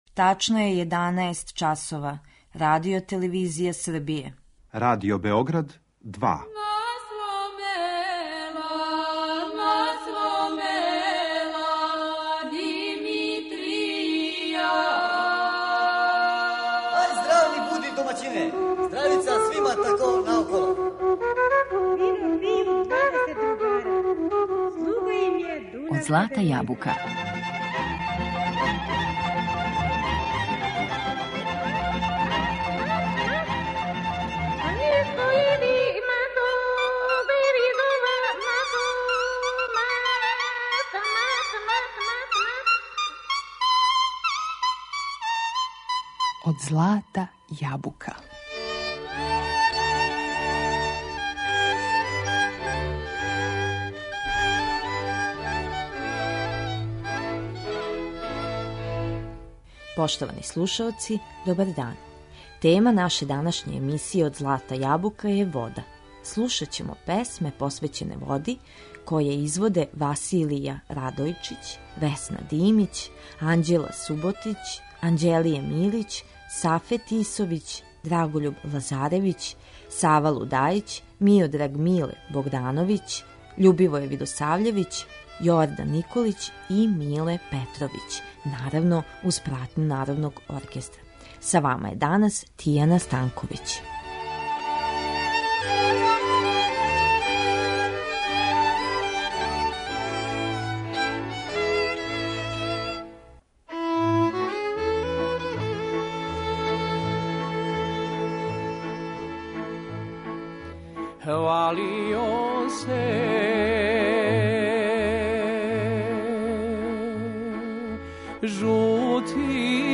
Слушаћемо песме у којима се она спомиње, а певају их